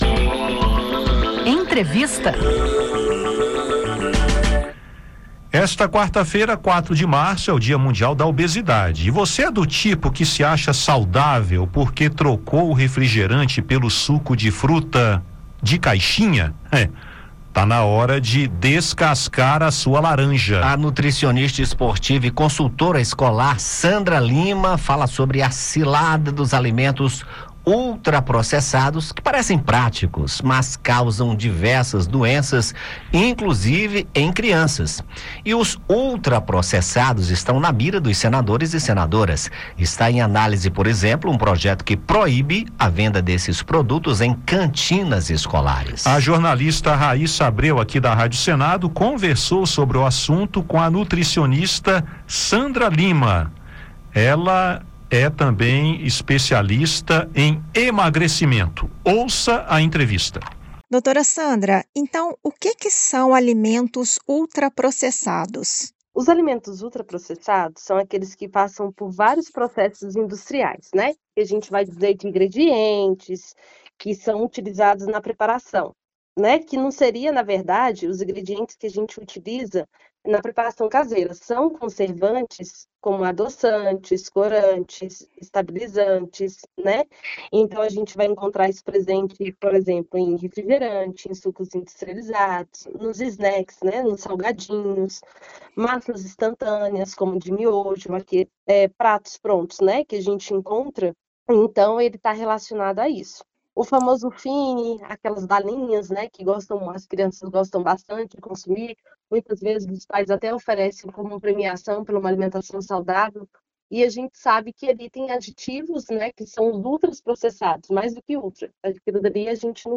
Além do aumento de peso, ultraprocessados podem levar à obesidade e causar doenças, inclusive em crianças, como diabetes e hipertensão. Acompanhe a entrevista e saiba como manter uma alimentação saudável.